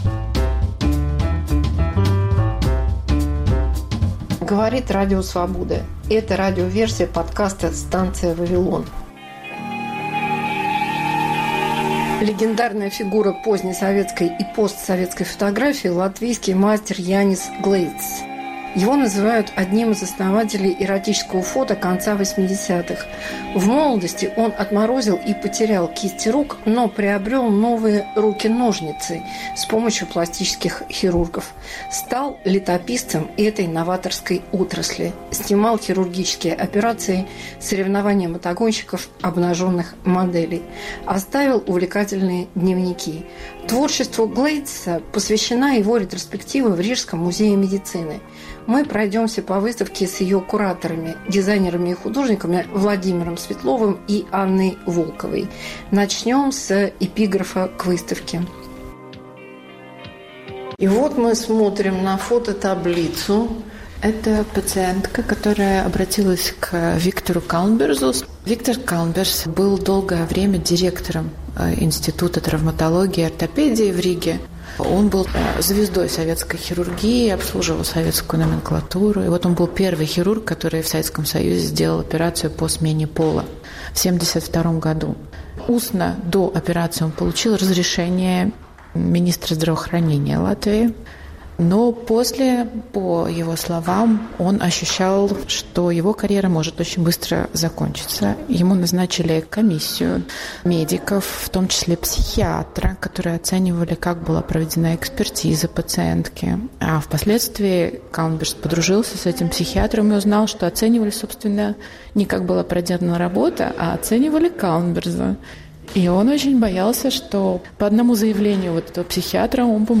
Мегаполис Москва как Радио Вавилон: современный звук, неожиданные сюжеты, разные голоса